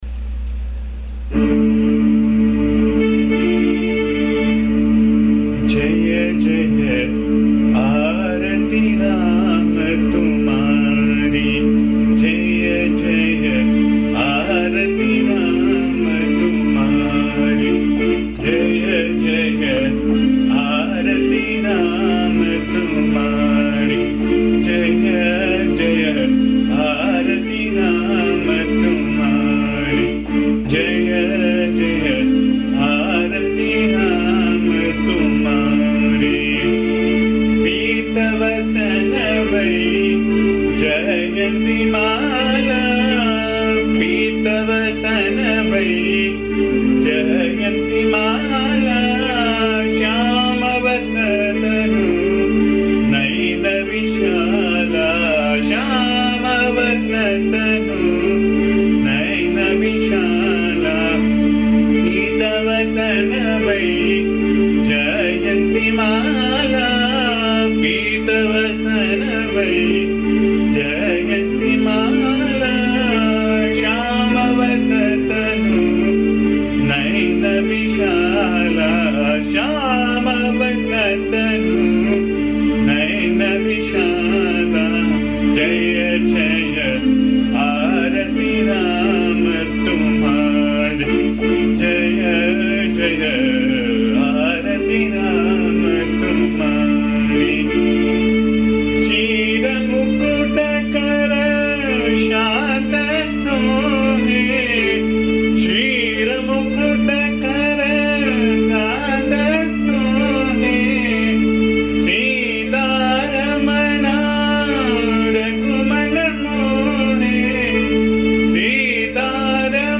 This is a song set in Abheri Raga (also known as Bhimpalasi). It praises the great avatara of Vishnu, Sri Rama.
Please bear the noise, disturbance and awful singing as am not a singer.
AMMA's bhajan song